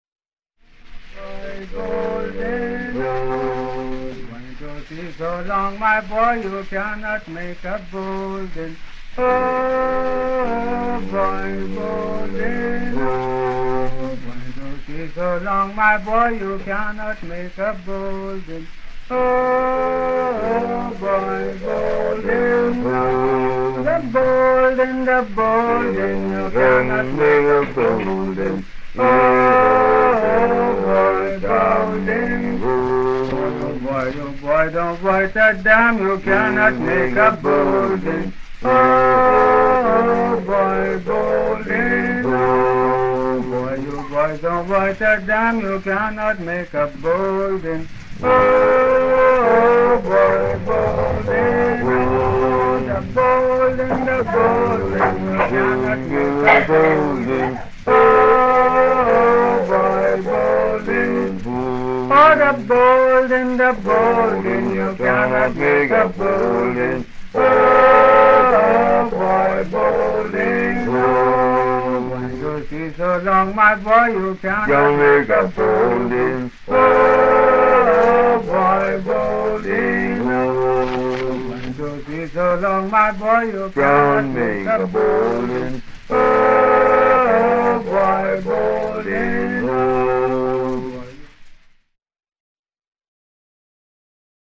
Bahamas 1935 - Vol 01 - Chanteys & anthems
Dalle registrazioni di Alan Lomax (anno 1935!) questi canti del mare e religiosi che provengono dall'arcipelago delle Bahamas